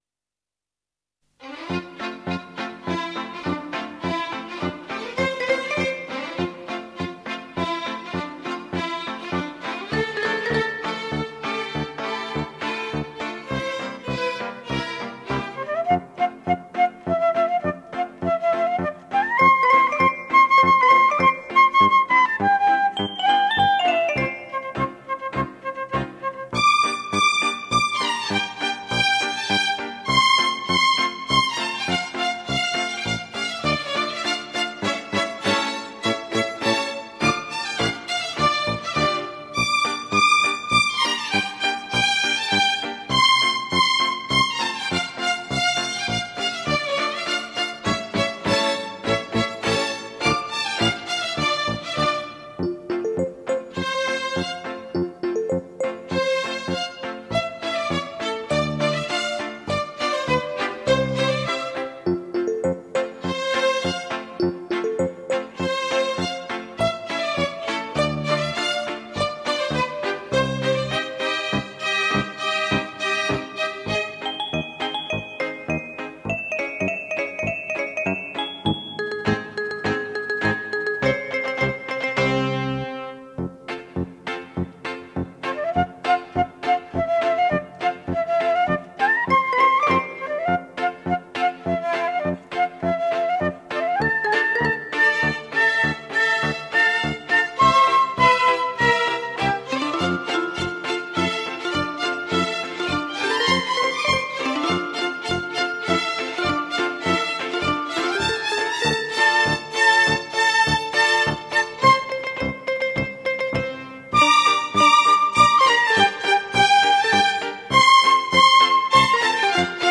进行曲 March Band